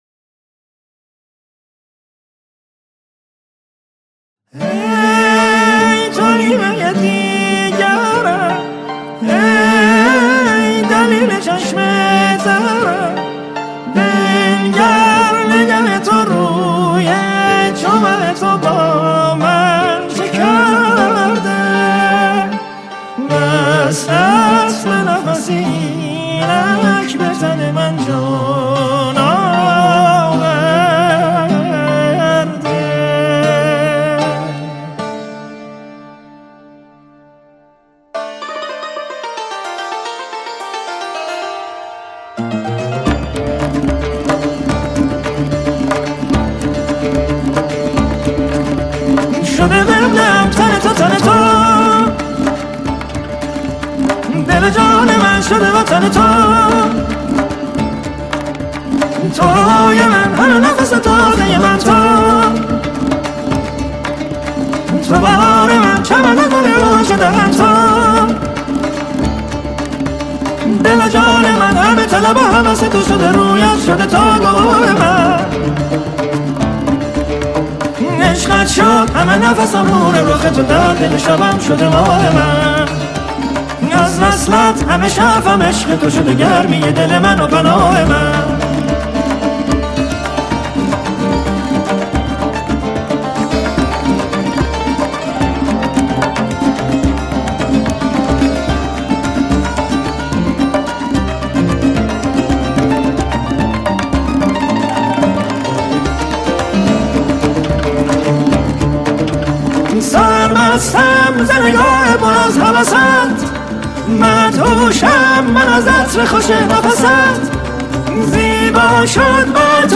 سنتور و عود الکترونیک
تنبک